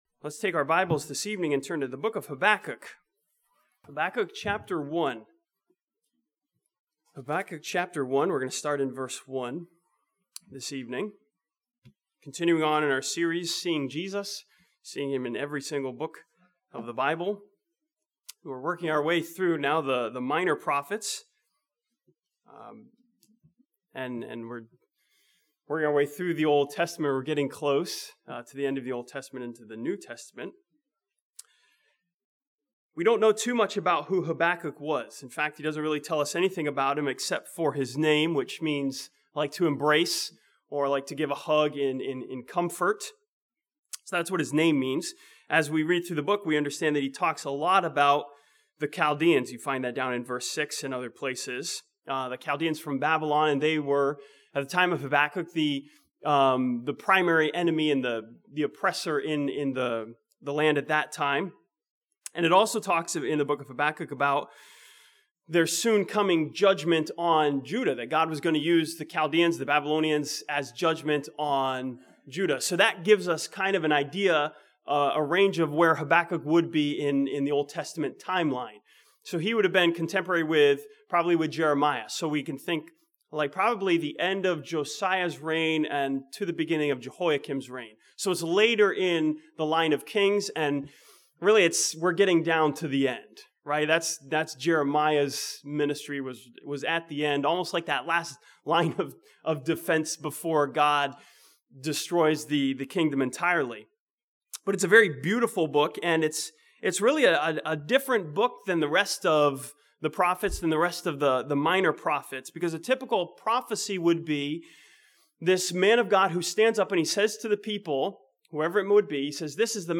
This sermon from Habakkuk chapter 2 sees Jesus and the promise of His coming as the object of our faith.